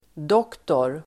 Uttal: [d'åk:tor]